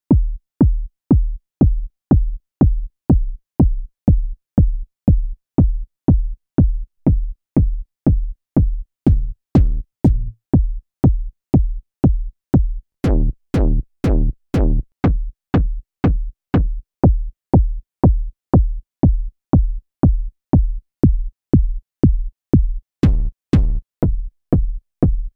SOS Tutorial 145 Ableton Live - Synth Kicks mit Operator
In diesem Tutorial wollen wir mit dem Operator eine Reihe synthetischer Kick Drums erstellen, die wir anschließend mit den Distortion-Tools von Ableton Live processen werden.